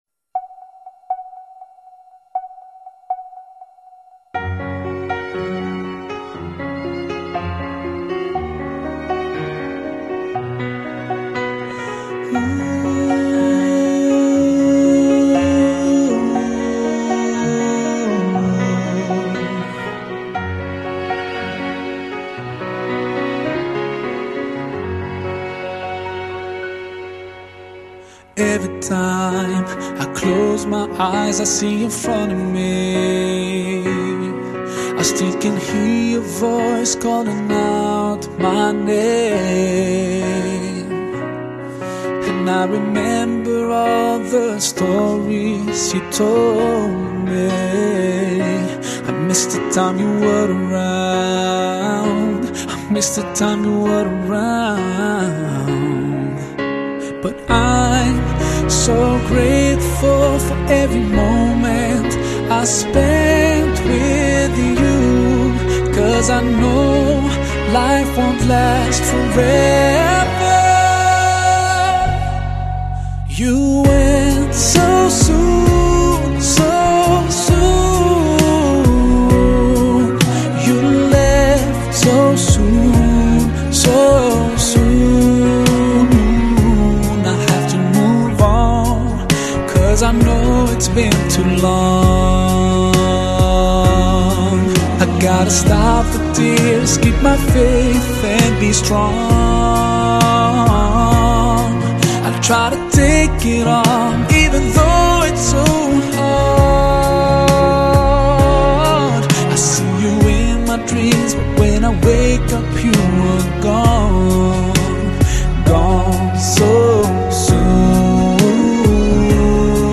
no music